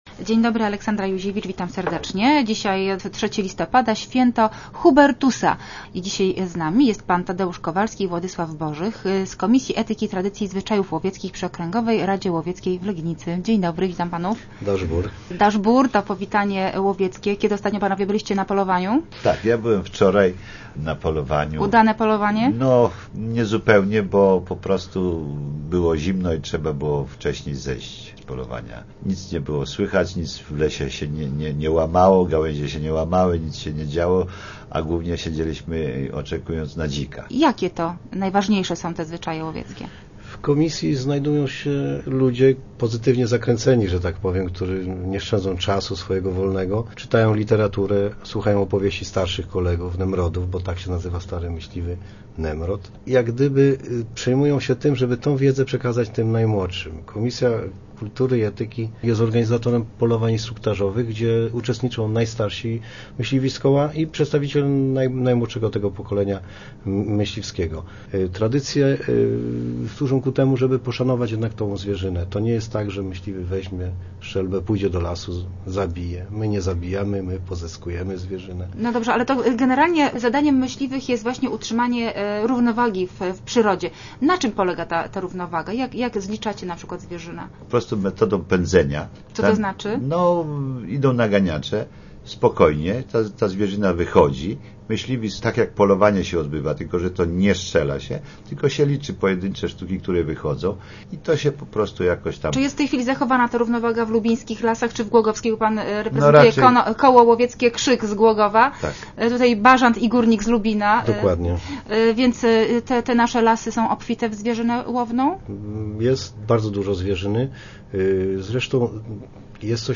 Start arrow Rozmowy Elki arrow Myśliwi: do zwierzyny mamy szacunek